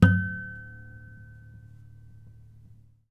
harmonic-09.wav